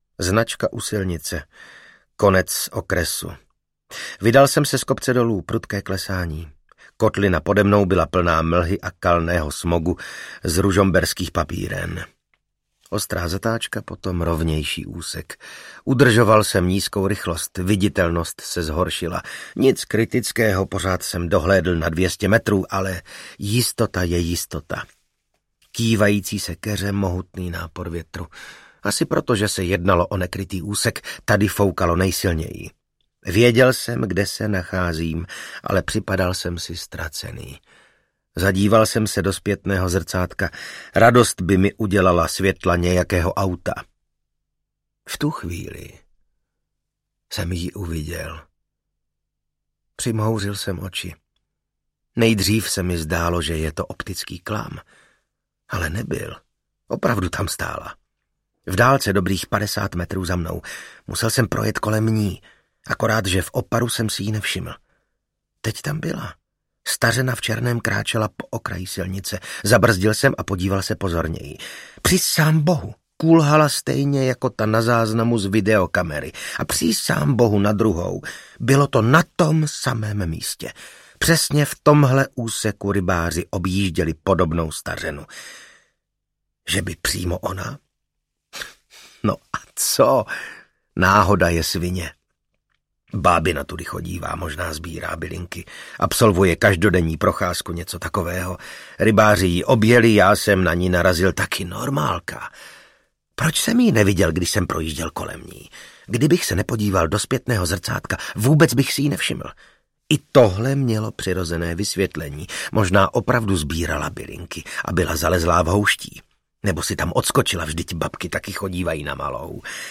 Smršť audiokniha
Ukázka z knihy
Čte Vasil Fridrich.
Vyrobilo studio Soundguru.